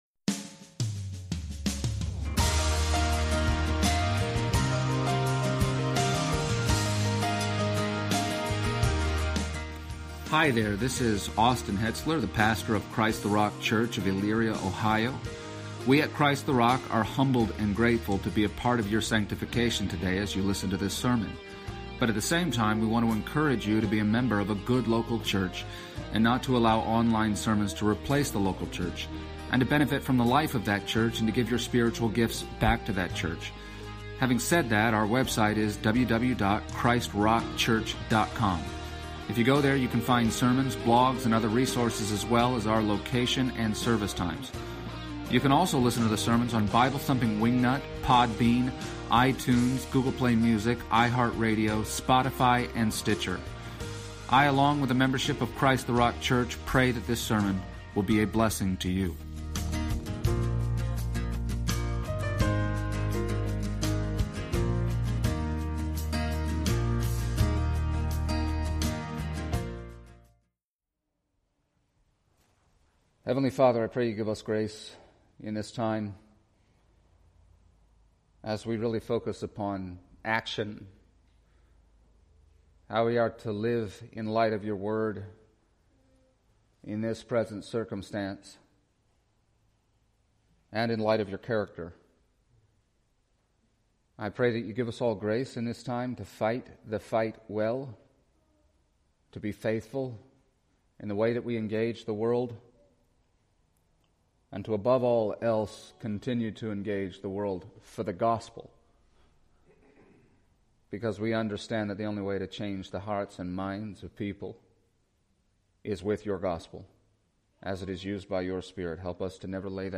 and the Christian Service Type: Sunday Morning %todo_render% « American Idolatry